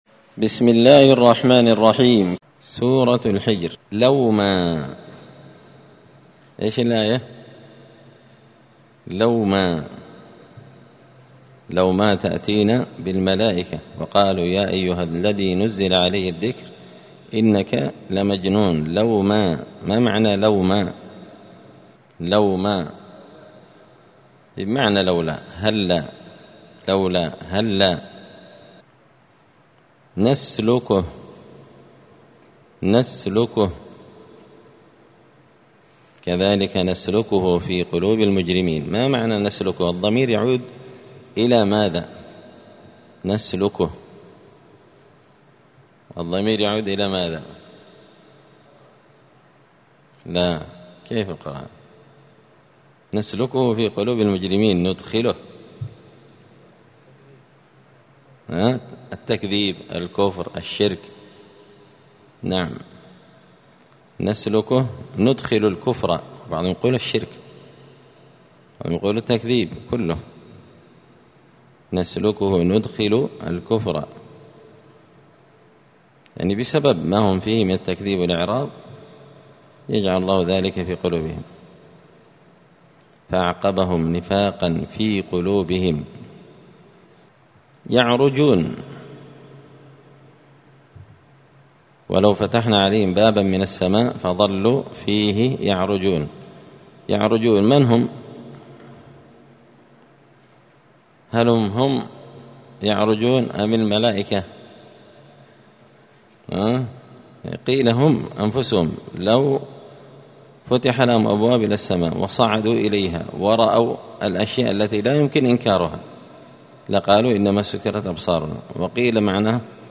دار الحديث السلفية بمسجد الفرقان بقشن المهرة اليمن
الخميس 25 رمضان 1445 هــــ | الدروس، المجالس الرمضانية لفهم معاني السور القرآنية، دروس القران وعلومة | شارك بتعليقك | 29 المشاهدات